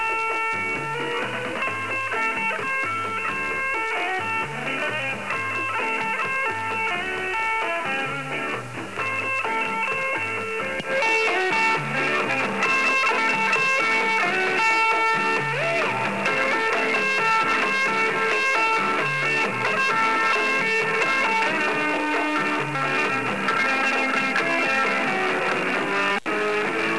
Each test starts in with the loose setting, then changes to the sharp setting about half way through.
2.70 kHz USB Mode
For the 2.70 kHz test, they sound very close to the same, perhaps even slightly better in the sharp setting. But one thing that makes it difficult to tell is that for the 2.70 kHz case, the volume is much louder with the sharp setting.
2.70LooseSharp.WAV